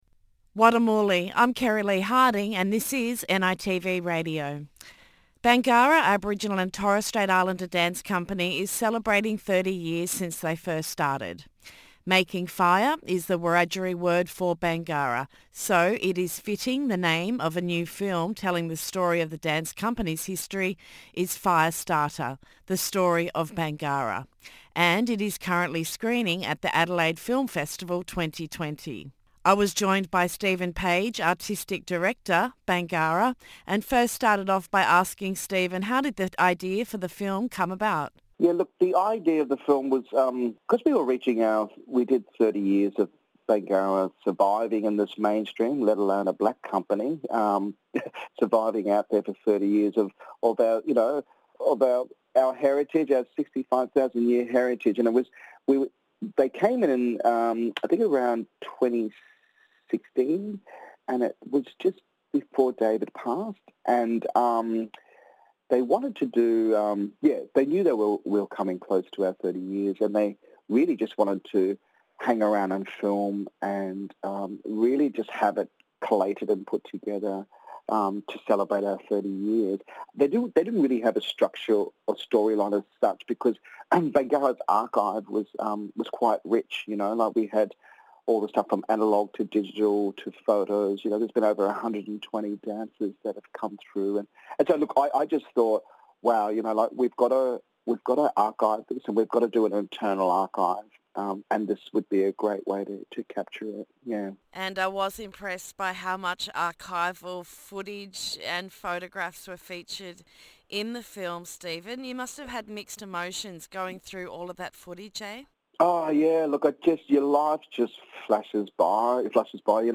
Stephen Page - Artistic Director Bangarra in a candid conversation and reflects back on the last 30 years of the history of Bangarra Dance Theatre.